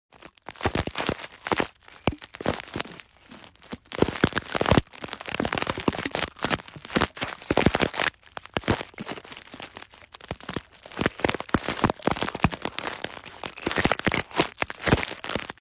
Putting on Rubber Gloves ASMR sound effects free download